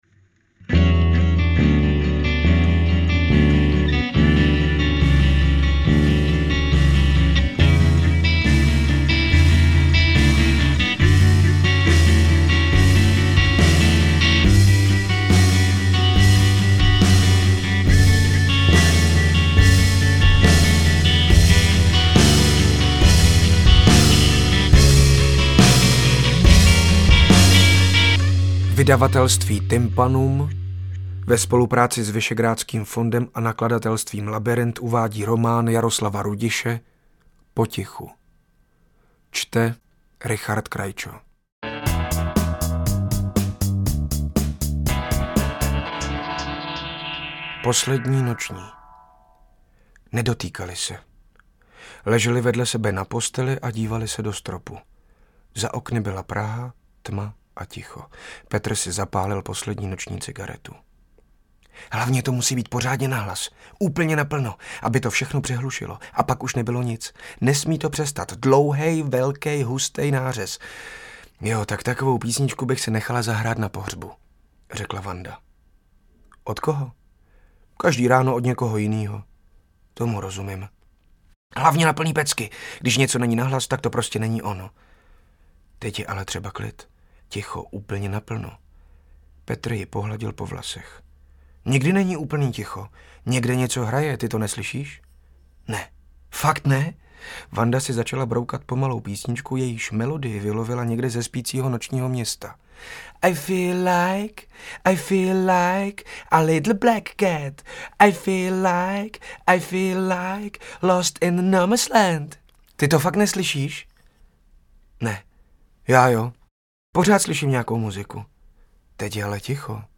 Audioverze románu současného českého autora ve formátu MP3.